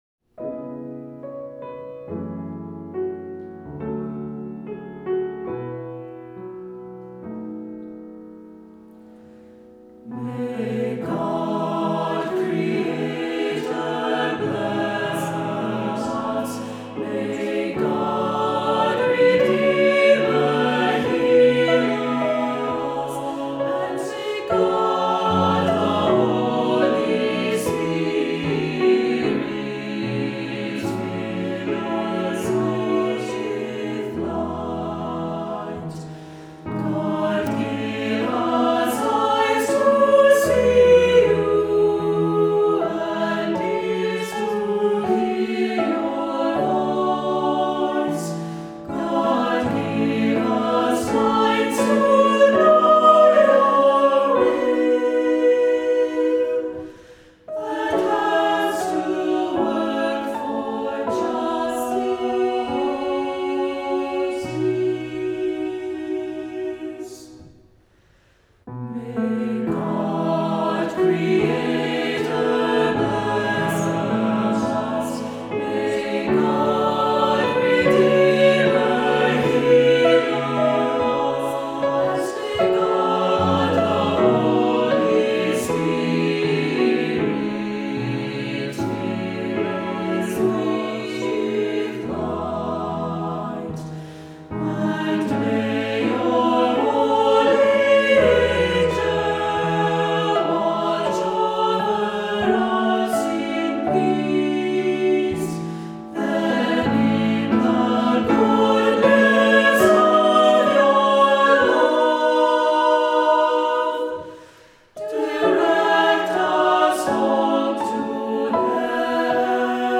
Voicing: Assembly, cantor,SATB,Unison Choir